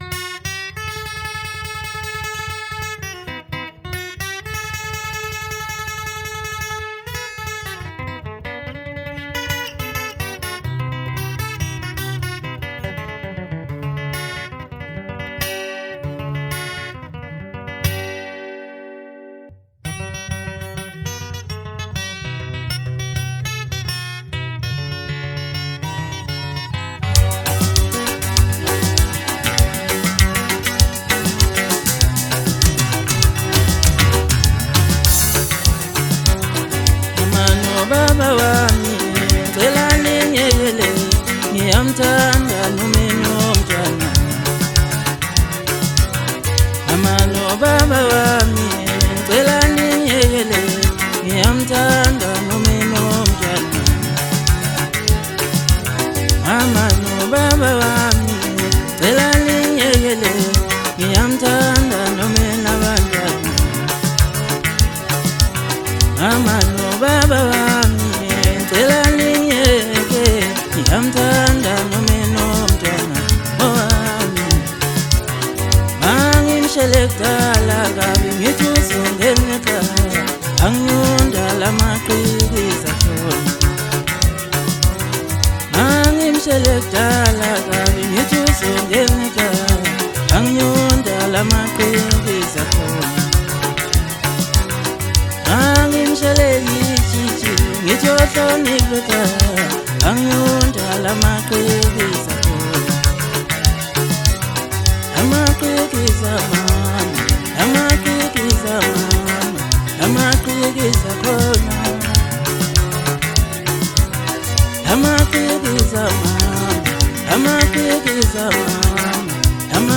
New Maskandi song